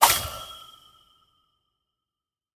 Minecraft Version Minecraft Version latest Latest Release | Latest Snapshot latest / assets / minecraft / sounds / block / trial_spawner / spawn_item2.ogg Compare With Compare With Latest Release | Latest Snapshot
spawn_item2.ogg